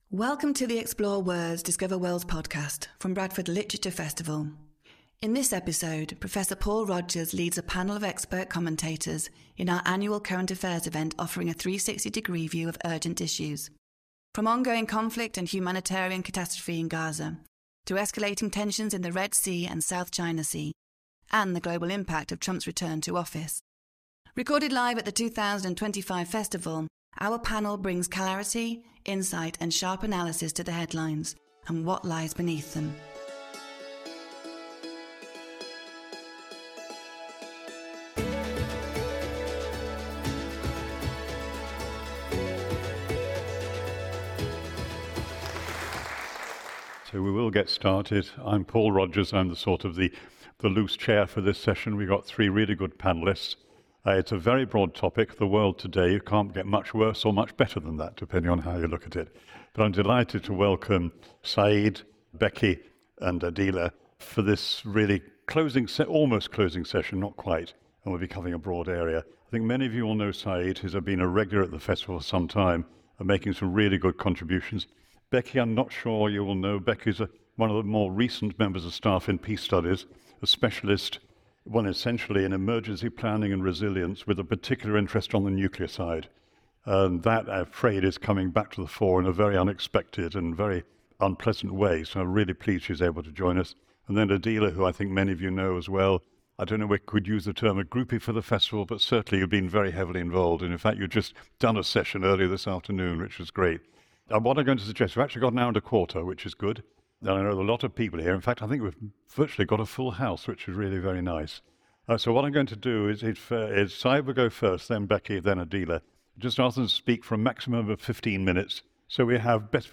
lead a panel of expert commentators in our annual current affairs event